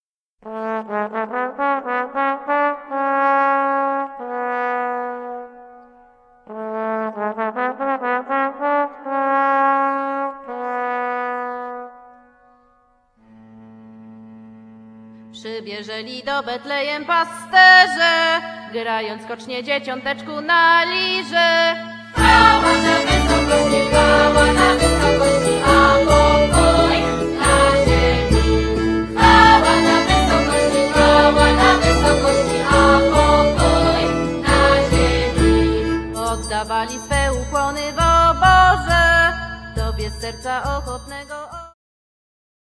cymbały
skrzypce żałobne
flety
puzon barokowy
bębny